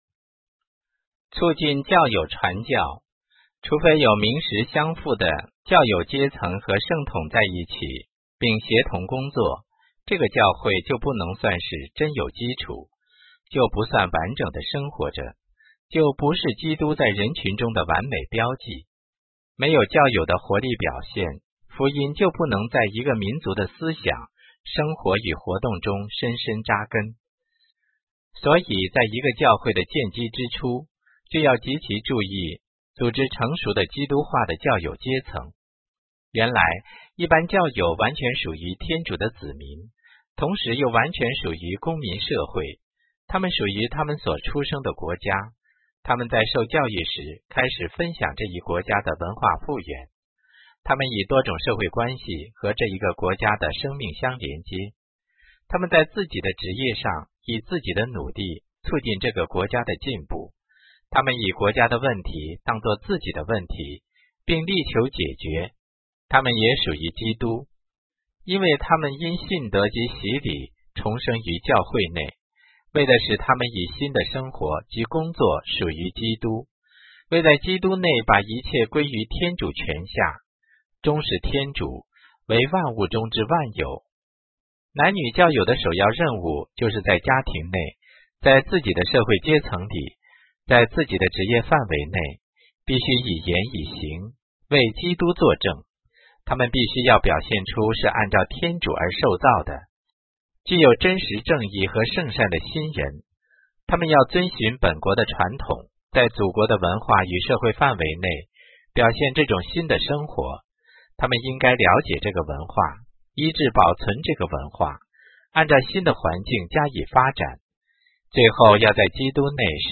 音频/有声阅读/梵二文献/第10部教会传教工作法令 • 在线资料库